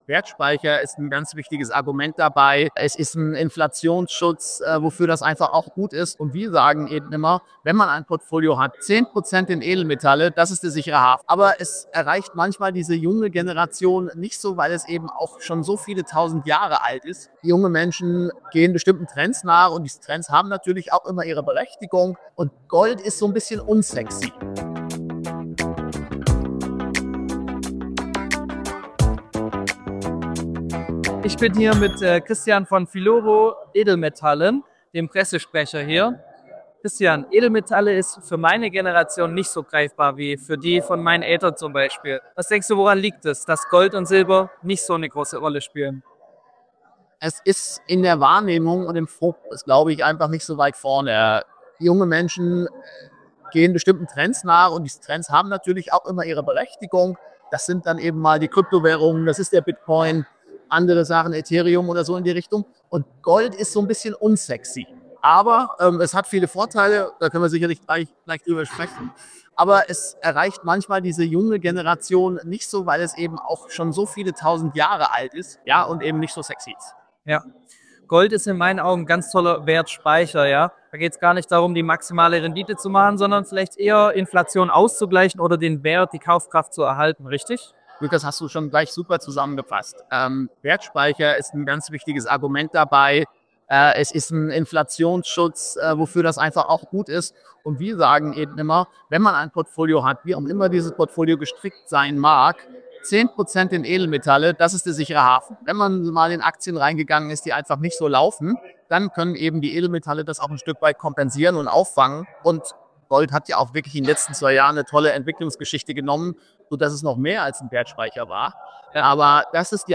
Interview | Anlegertag Düsseldorf 2025 (3/8)